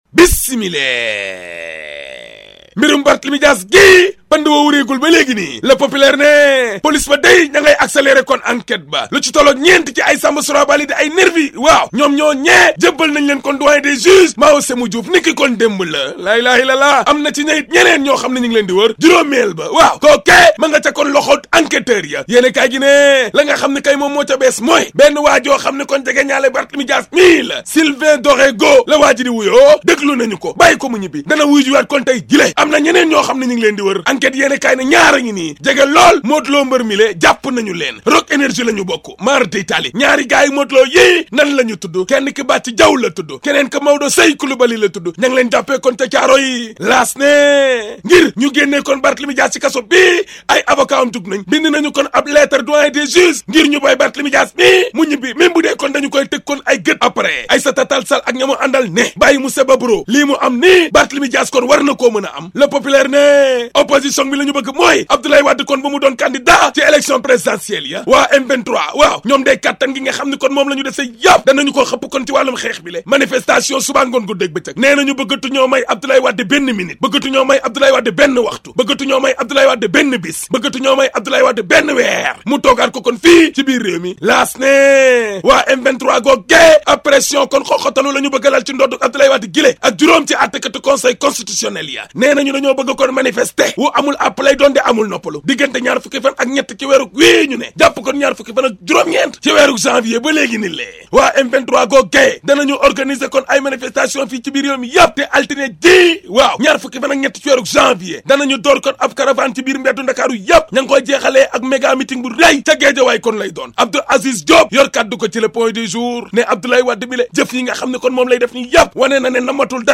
La revue de presse d'Ahmed Aidara du 18 Janvier (ZIK FM)